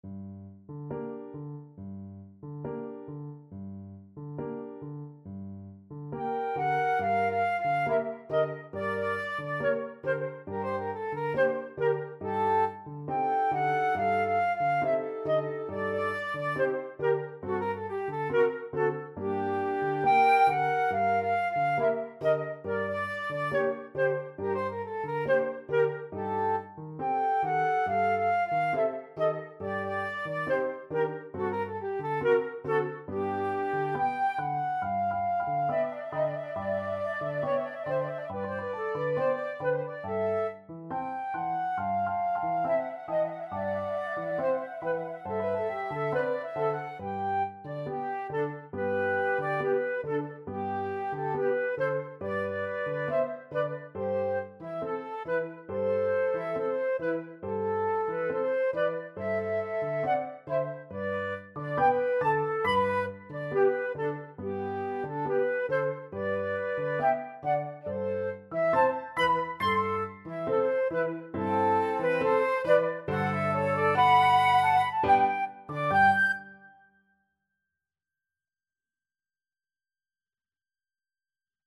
Free Sheet music for Flute Duet
Flute 1Flute 2Piano
2/4 (View more 2/4 Music)
G minor (Sounding Pitch) (View more G minor Music for Flute Duet )
Allegretto quasi Andantino =69 (View more music marked Andantino)
Flute Duet  (View more Intermediate Flute Duet Music)
Classical (View more Classical Flute Duet Music)